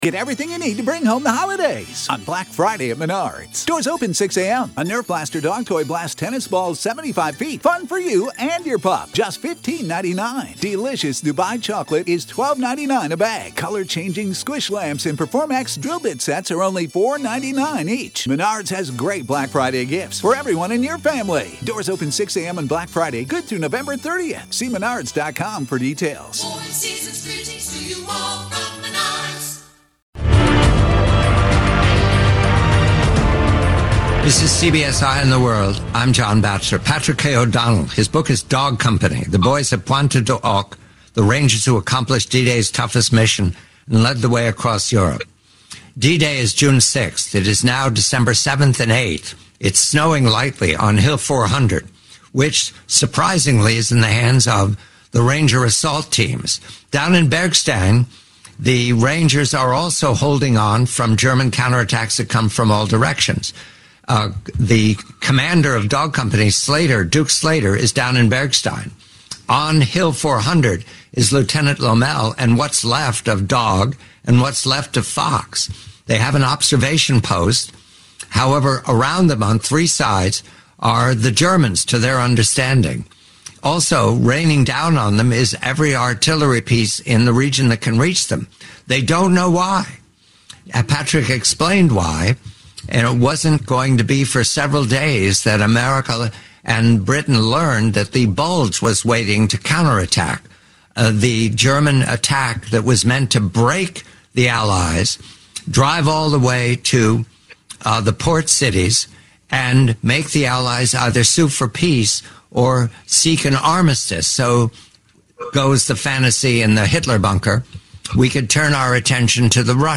Blackstone Audio, Inc. Audible Audiobook – Unabridged